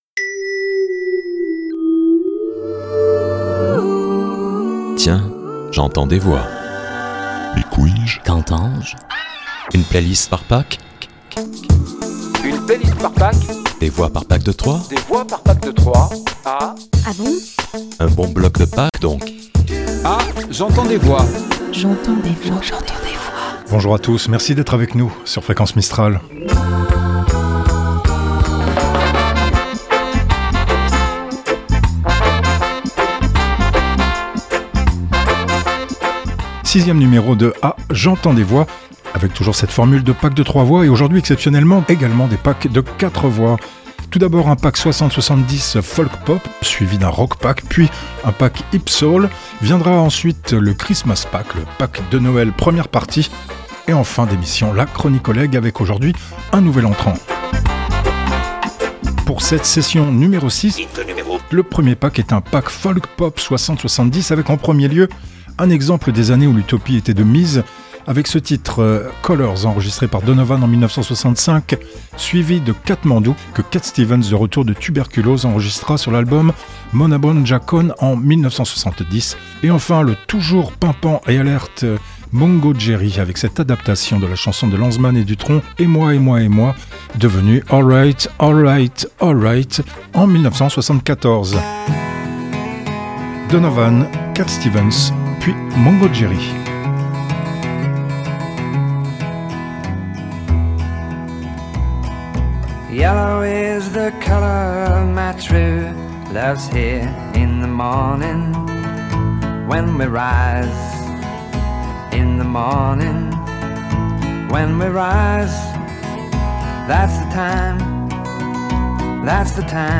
PACK 60s70s FOLKPOP
ROCKPACK
PACK HIPSOUL
Générique, jingles, voix additionnelles